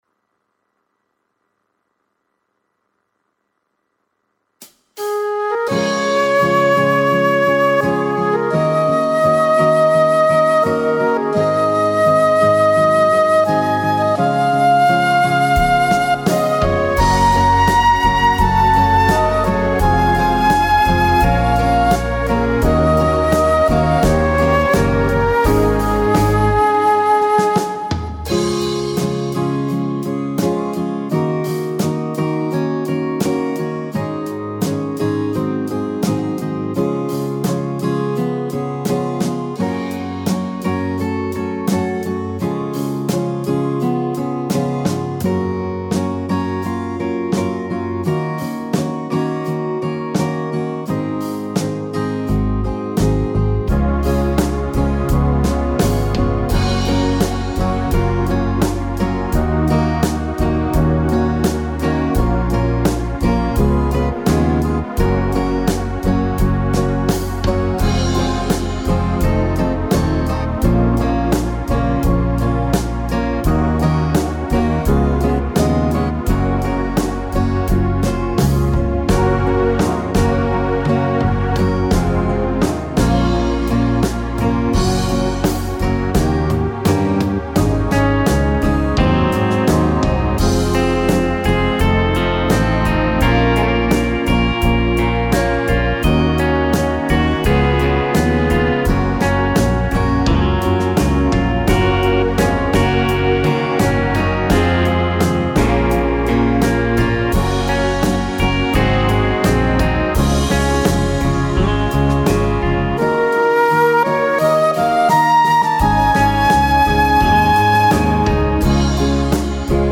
Tone Tốp (C#)
Đạo ca Mừng Kỷ niệm ngày thành lập Thánh Thất Từ Quang